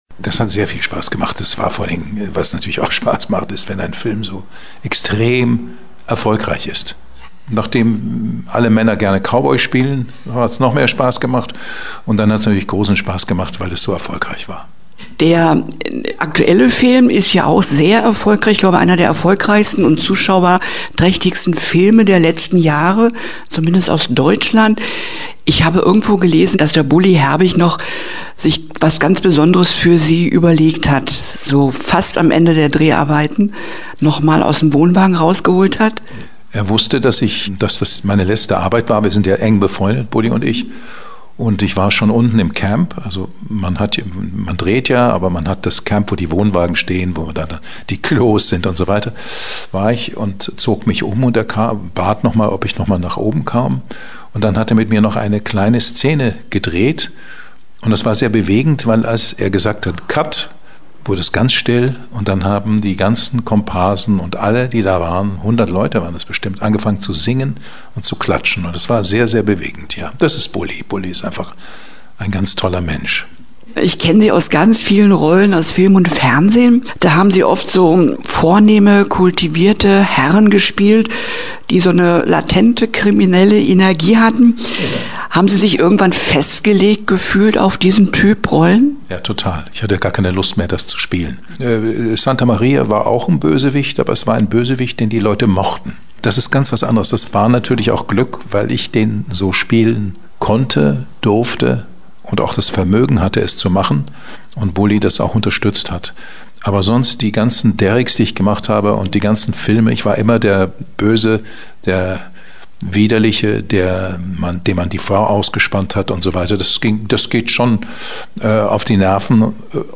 Interview-Sky-du-Mont.mp3